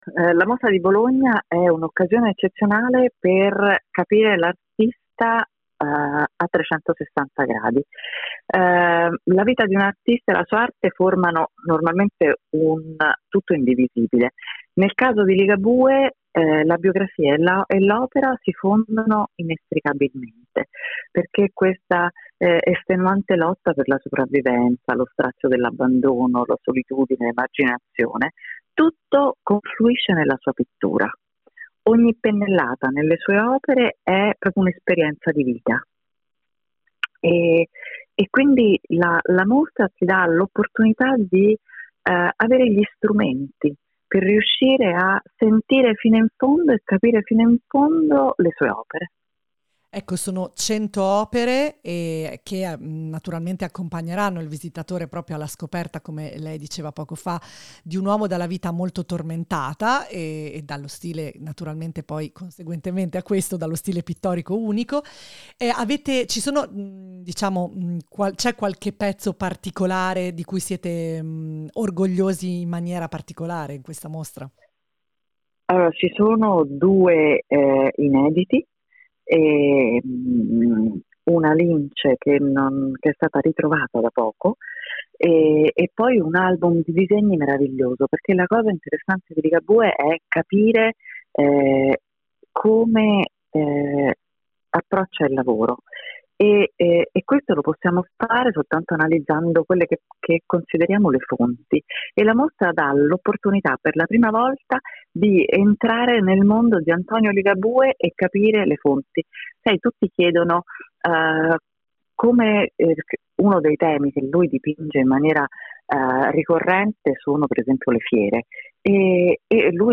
Home Magazine Interviste Antonio Ligabue in mostra a Bologna